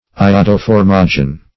Search Result for " iodoformogen" : The Collaborative International Dictionary of English v.0.48: Iodoformogen \I`o*do*for"mo*gen\, n. [Iodoform + -gen root of gi`gnesqai to be born.]
iodoformogen.mp3